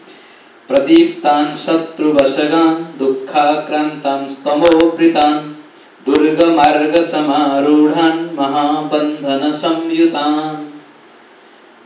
anuṣṭubh
msa17_29_sing.wav